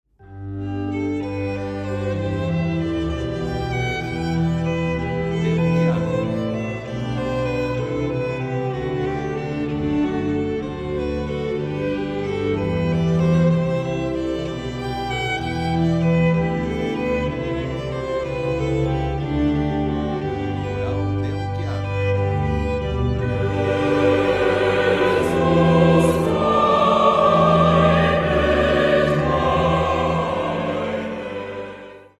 músicas para casamentos